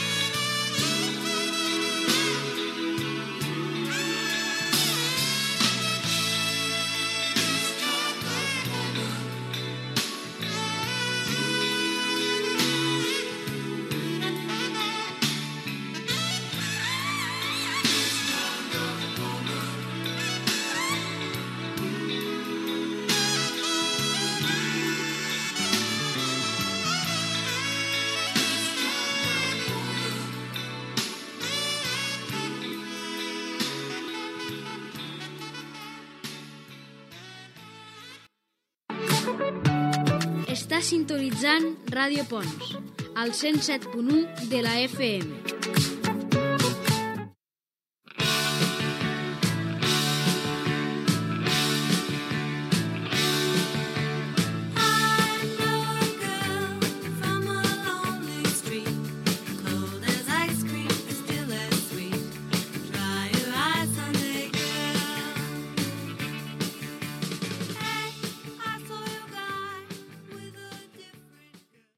Indicatiu de l'emissora i tema musical